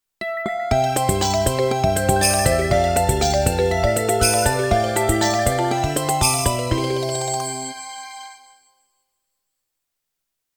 少々長い曲もありますが、発車ベルとしての機能を果たせるように心掛け製作しました。